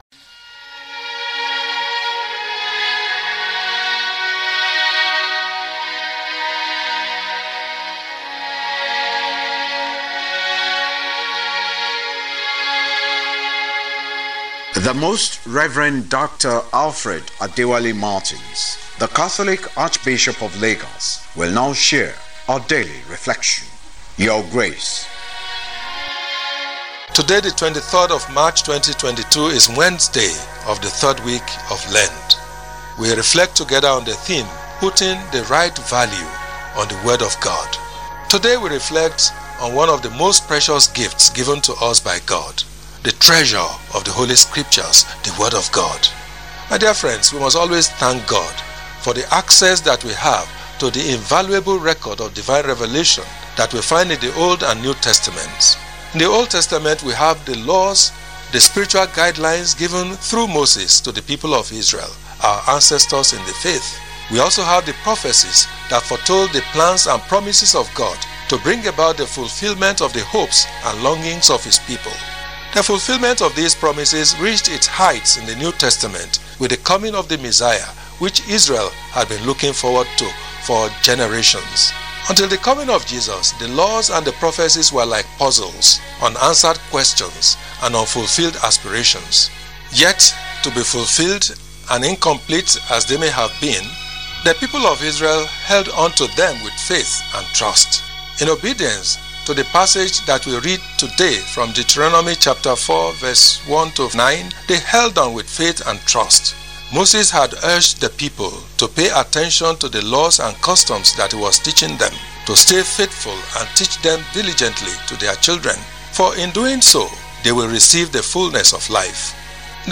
LENTEN REFLECTION WITH ARCHBISHOP MARTINS. WEDNESDAY 23 MARCH 2022.
Lenten-Talk-Wednesday-23.mp3